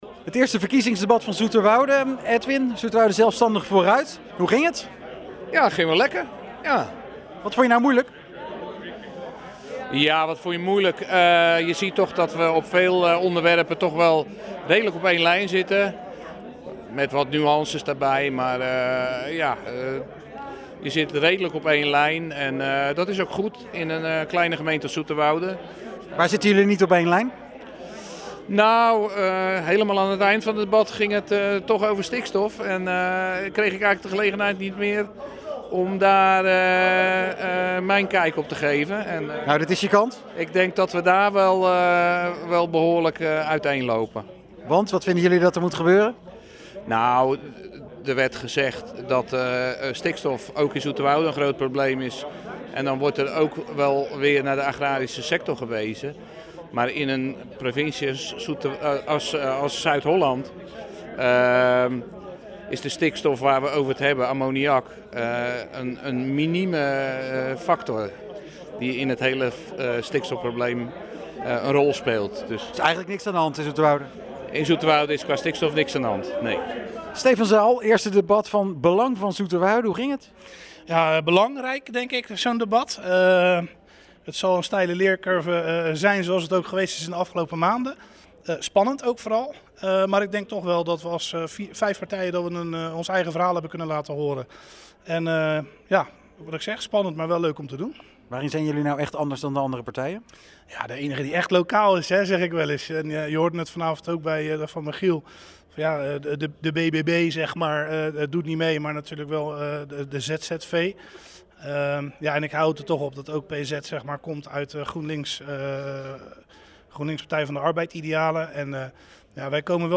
De Ondernemersvereniging Zoeterwoude (OVZ) organiseerde een ondernemersdebat.
na afloop van het ondernemersdebat in gesprek met de deelnemende lijsttrekkers: